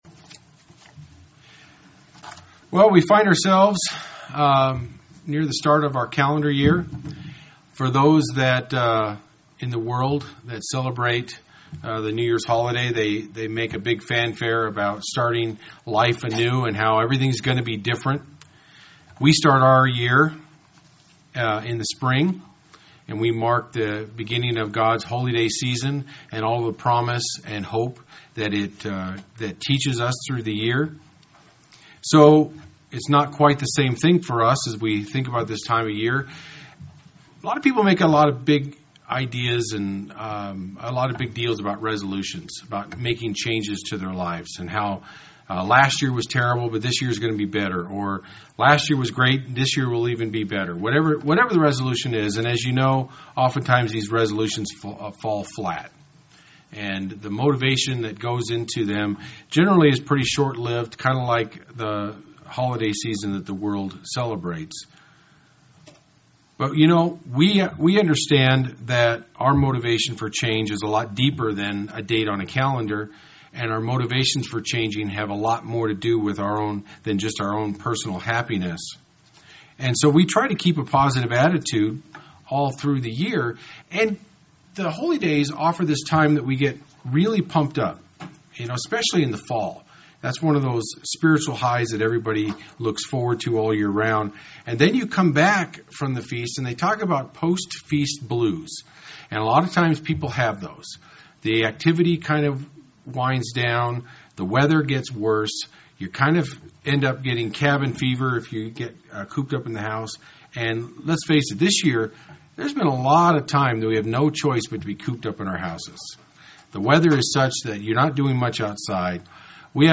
UCG Sermon Overcoming discouragement Studying the bible?